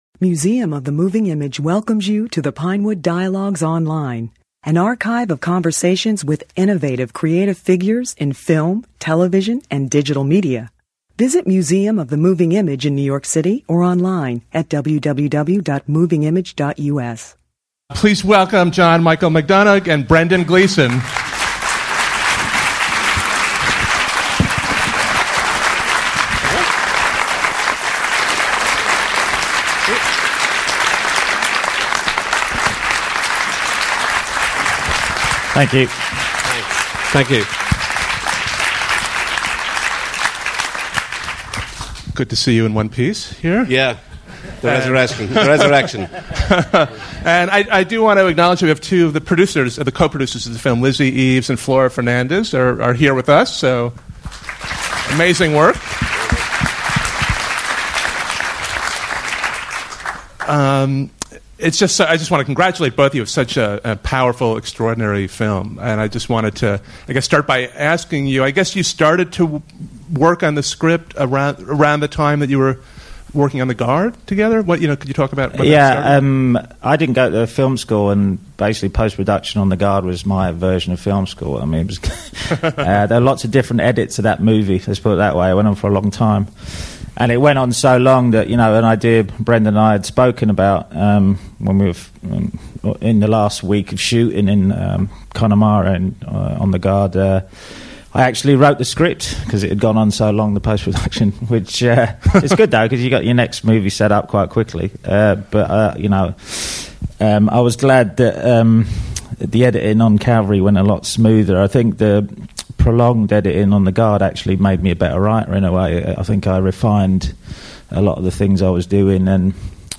In Calvary, a masterfully made, darkly comic film that is bound to be one of the most talked-about movies of the year, the great actor Brendan Gleeson plays an Irish priest who is marked for death by a parishioner and given one week to live. Writer/director John Michael Donagh, who has crafted a literary and deeply moving film, and the phenomenal actor Brendan Gleeson (who also starrred in McDonagh's film The Guard), took part in a lively and memorable discussion after a special screening for Museum members.